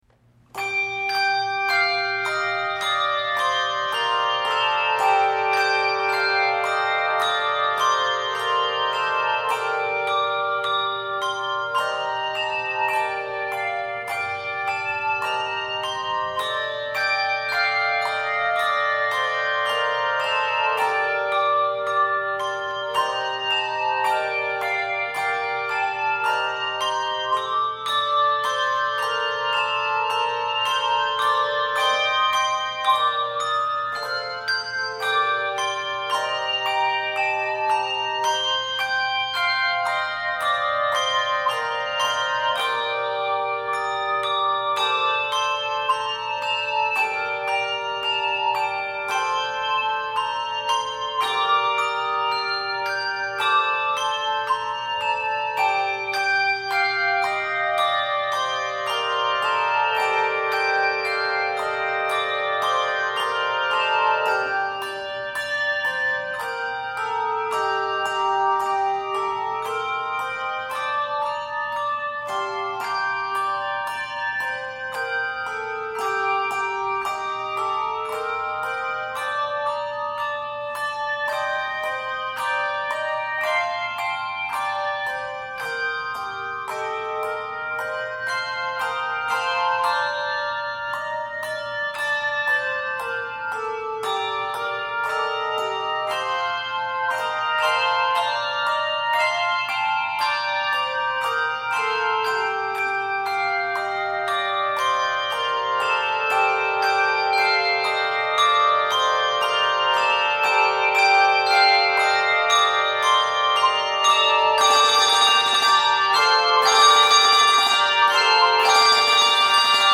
Composer: 18th Century English Carol
Voicing: Handbells 2 Octave